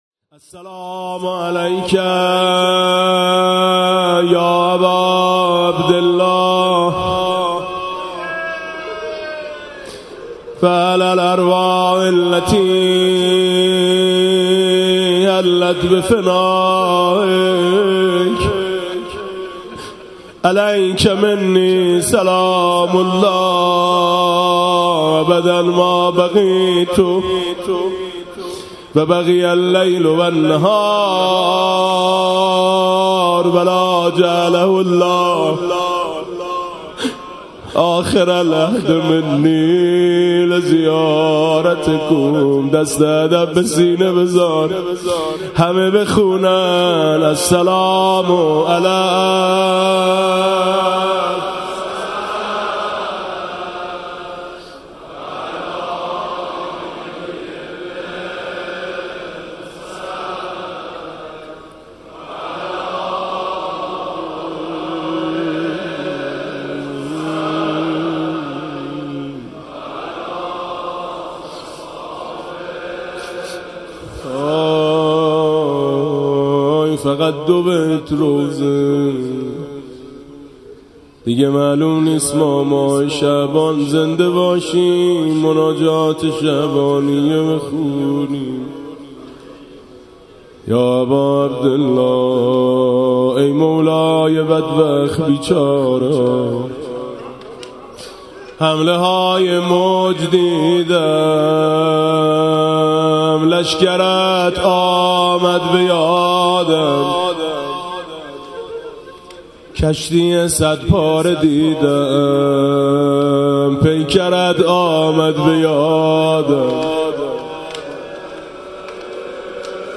[آستان مقدس امامزاده قاضي الصابر (ع)]
مناسبت: قرائت مناجات شعبانیه
با نوای: حاج میثم مطیعی
سلام به امام حسین (ع) و روضه‌ی پایانی